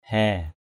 /hɛ:/